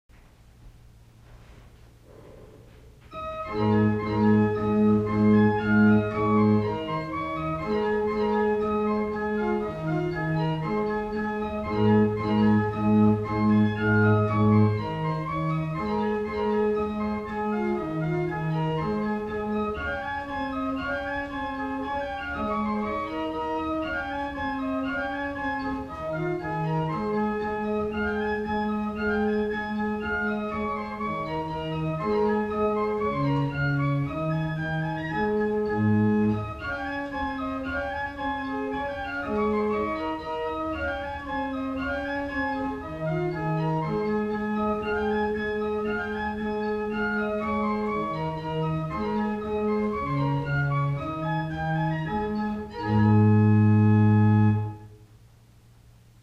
1802 Tannenberg Organ
Hebron Lutheran Church - Madison, VA
Listen to The Virginia Reel by Alexander Reinagle played on Principal dulcis 8', Gedackt 8' and Principal 4' by clicking